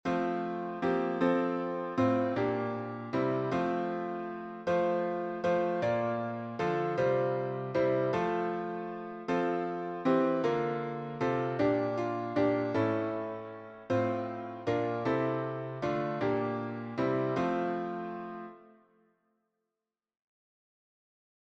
Medieval French melody